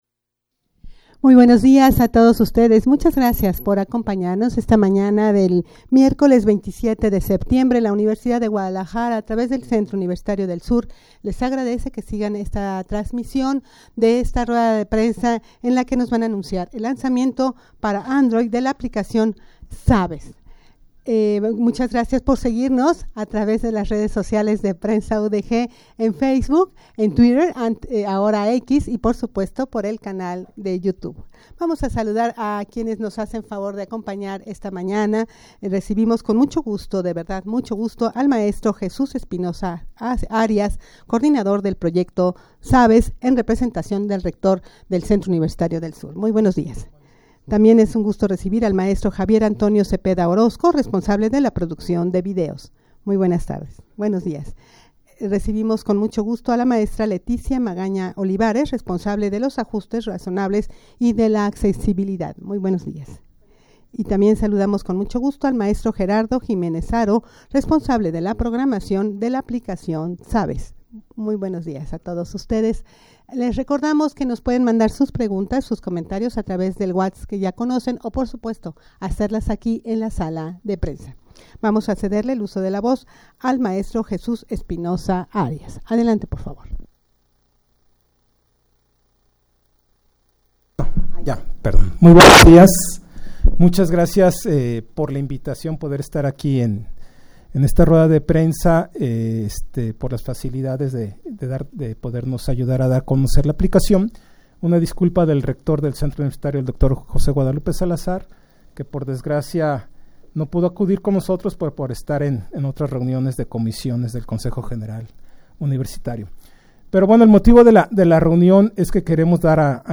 rueda-de-prensa-para-anunciar-el-lanzamiento-para-android-de-la-aplicacion-zaves.mp3